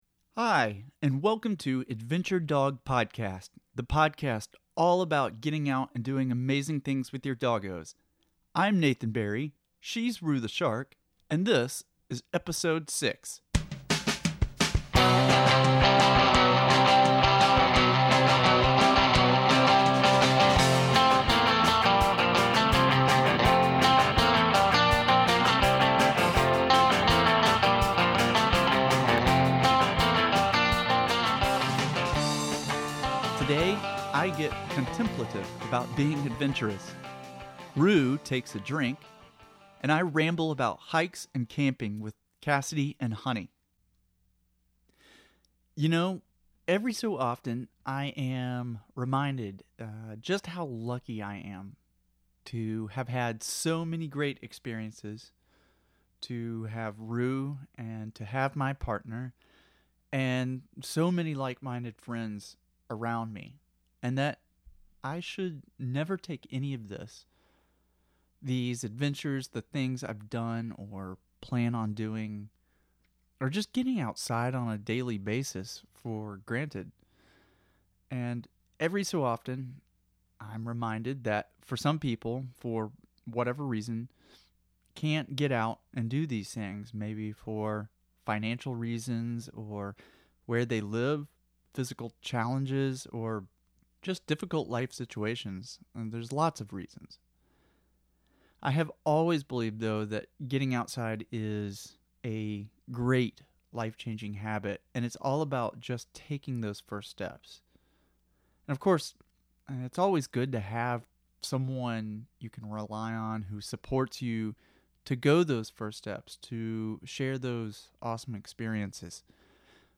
Today’s interview